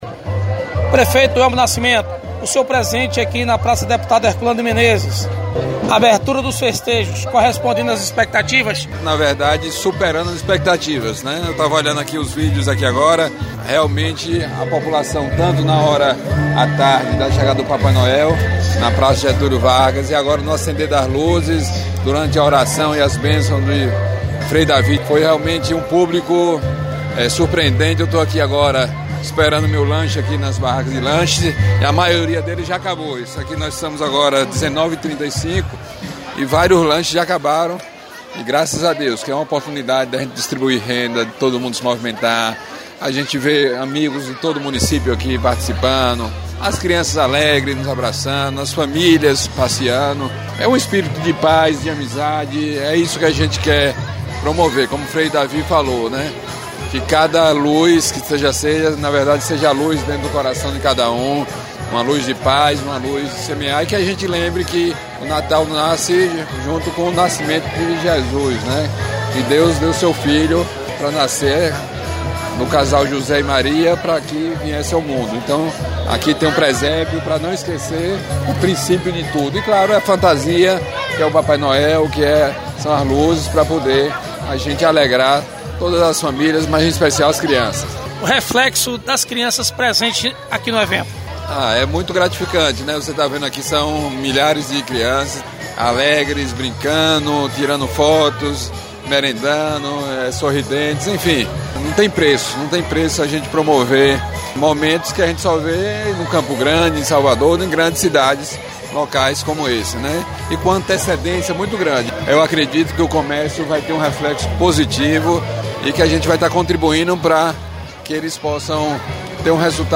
Prefeito Elmo Nascimento, comentando a expectativa na abertura dos festejos natalino